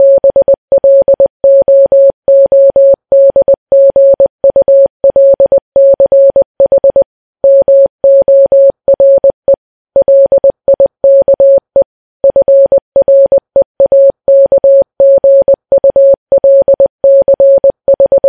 morse.mp3.mp3